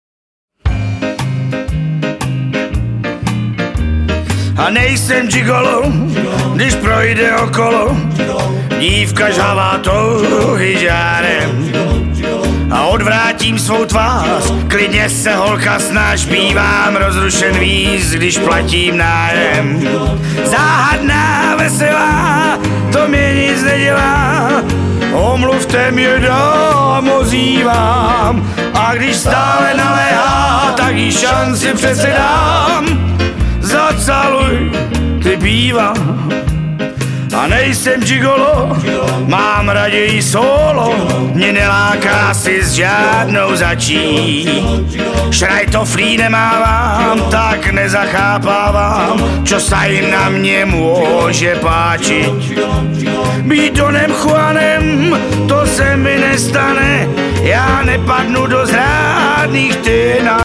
Rock’n’Roll!